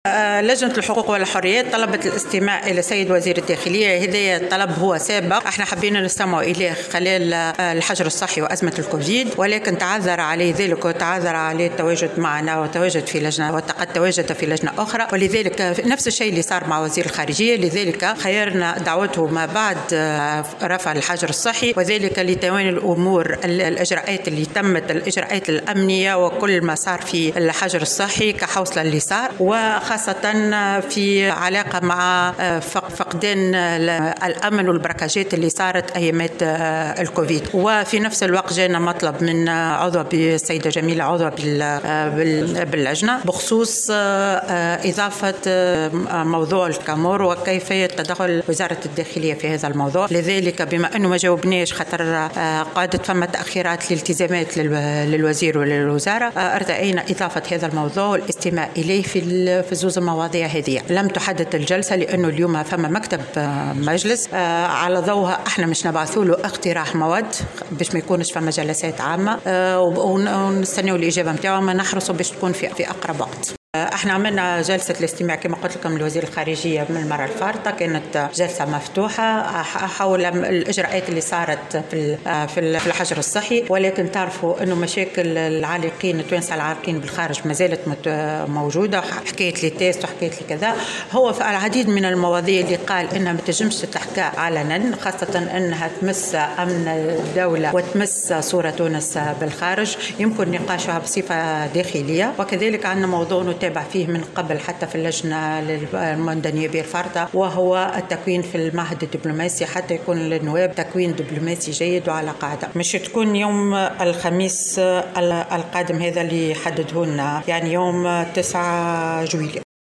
وقالت رئيسة اللجنة، سماح دمق، في تصريح اليوم لمراسل "الجوهرة أف أم" إنه سيتم الخميس القادم الاستماع لوزير الخارجية، نور الدين الري بخصوص عملية إجلاء التونسيين العالقين بالخارج (جلسة سرية بمقر الوزارة وبطلب من الوزير).